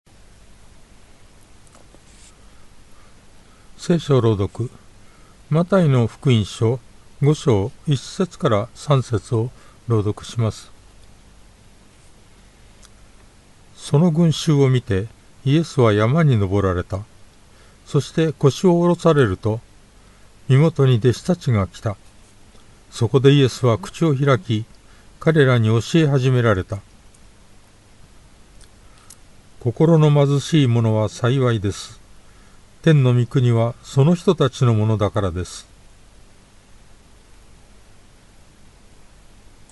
BibleReading_Math5.1-13.mp3